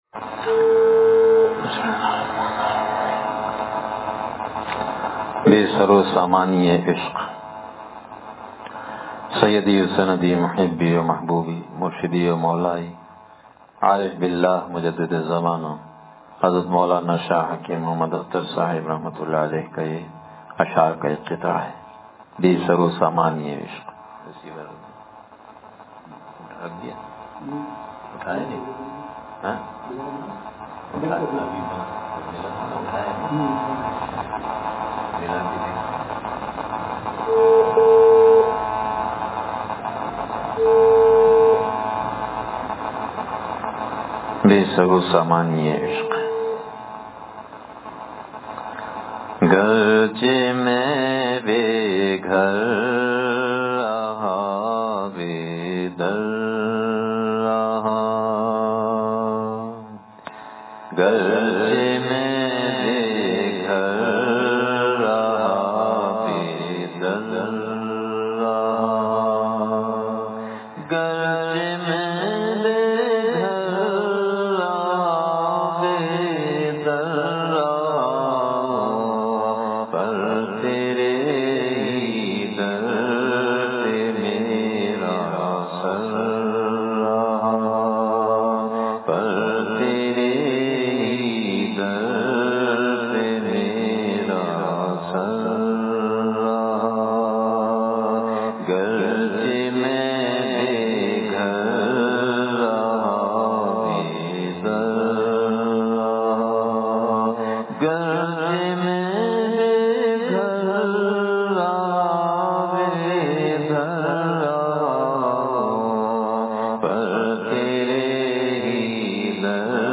وعظ اللہ تعالی کی شانِ جذ ب (حصہ دوئم)